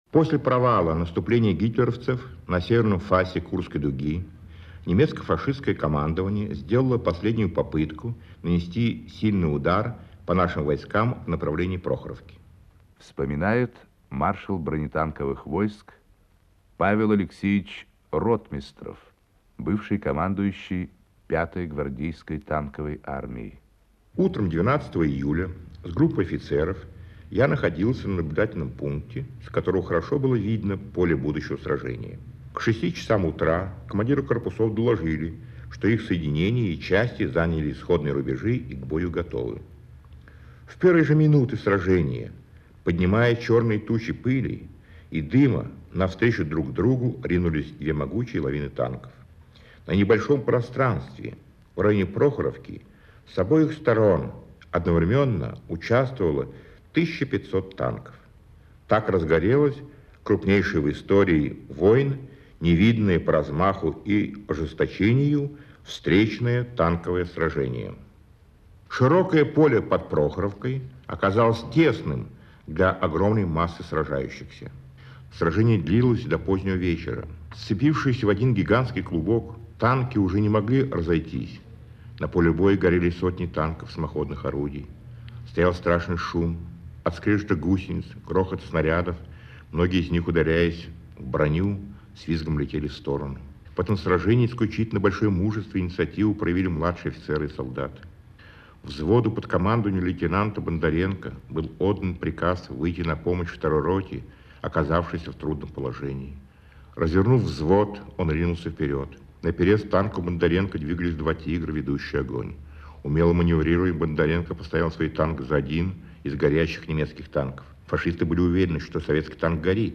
Главный Маршал бронетанковых войск Павел Ротмистров рассказывает о танковом сражении у Прохоровки во время Курской битвы (Архивная запись.)